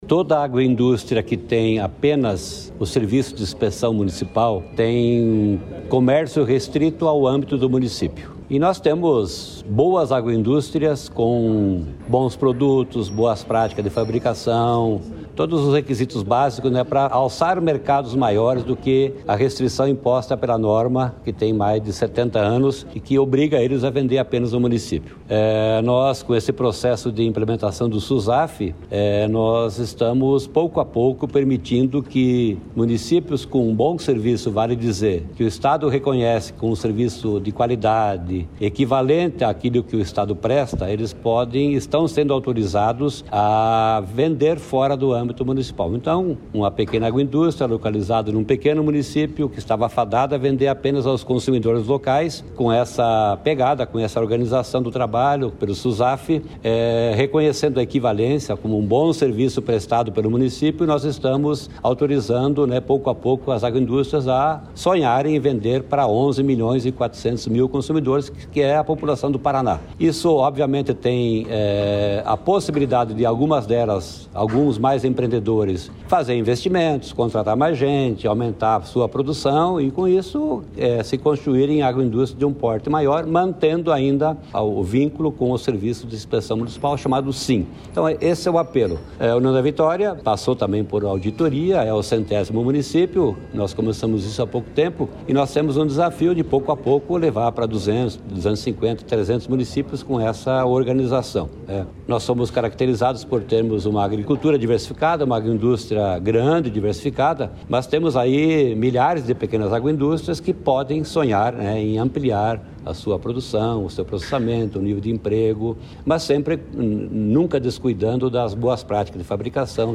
Sonora do secretário da Agricultura e do Abastecimento, Norberto Ortigara, sobre o Paraná ter 100 municípios com selo de sanidade agroindustrial da Adapar